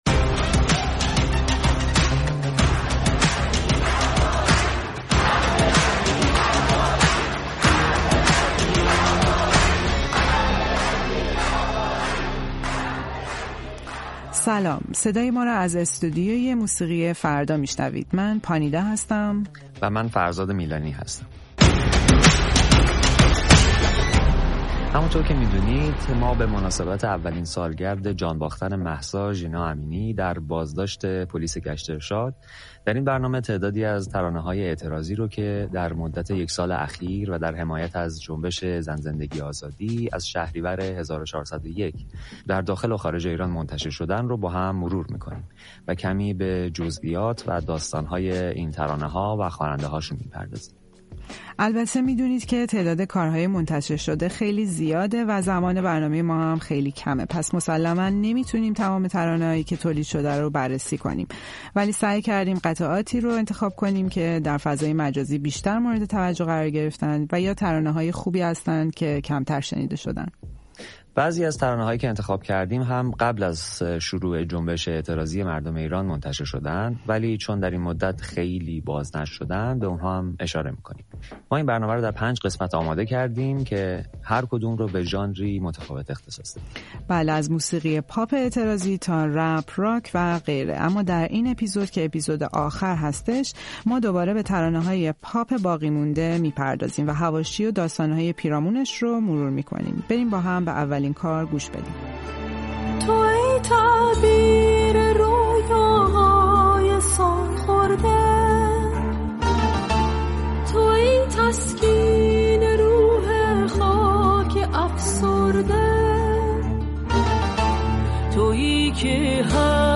در استودیو موسیقی فردا